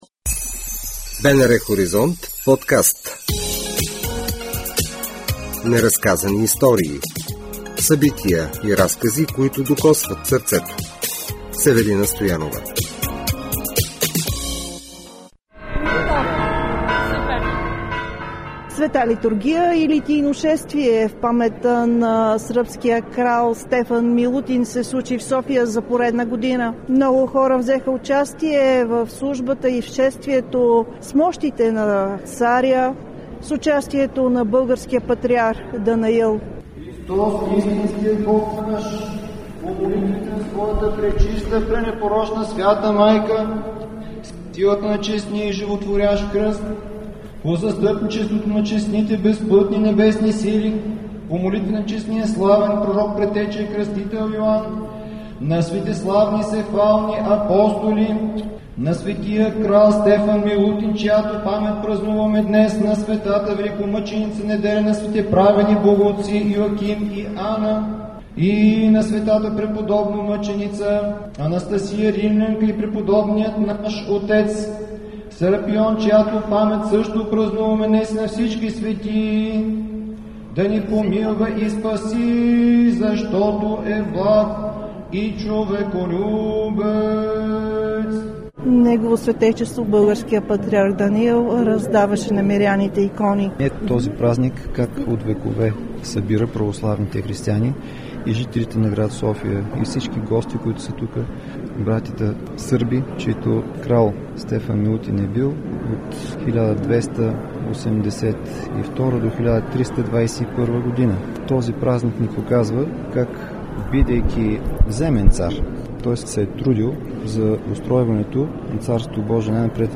Неразказани истории ви пренася в един от столичните домове на вярата – храм „Света Неделя“, където патриарх Даниил отслужи Патриаршеска литургия.